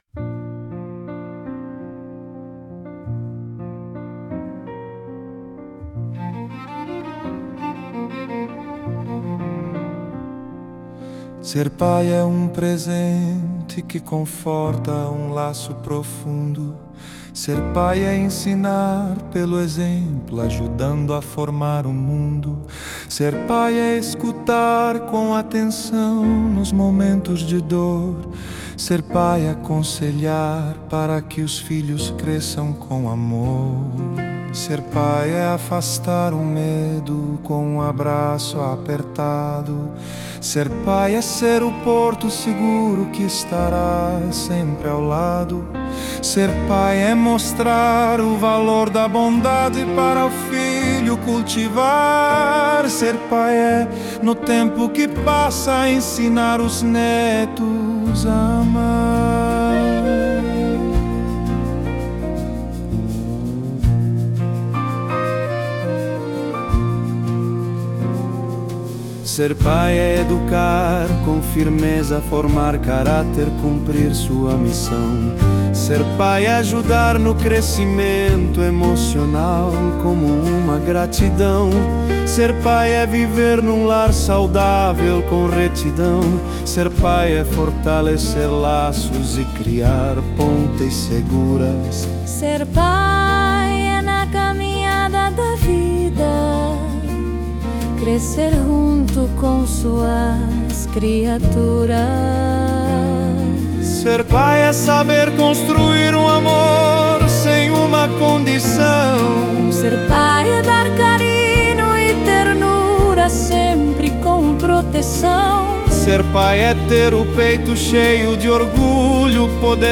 música, arranjo e voz: IA